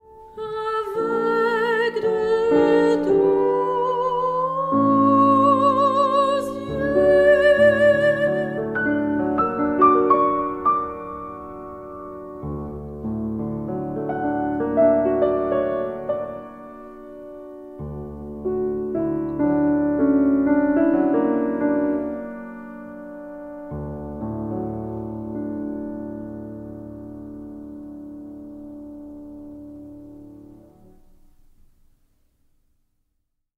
(link to recording) (I+ prolongs tonic imparting a whole-tone coloration) (also contains a Sw+6)